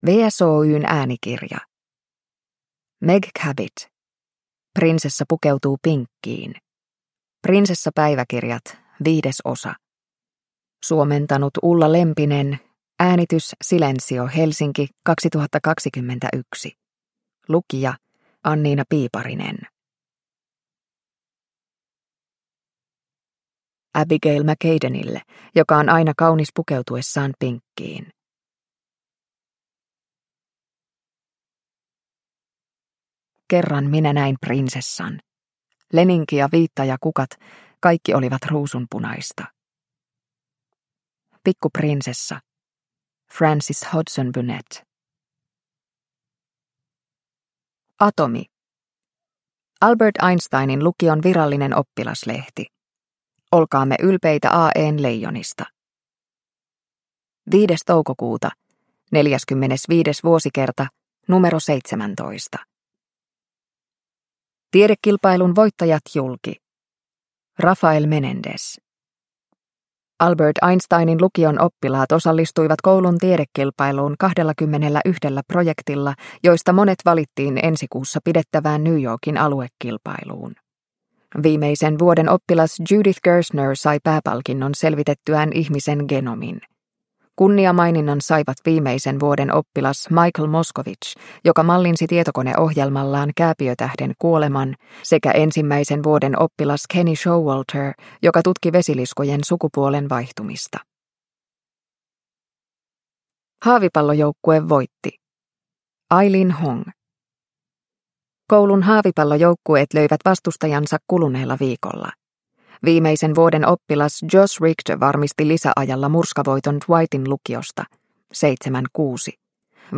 Prinsessa pukeutuu pinkkiin – Ljudbok – Laddas ner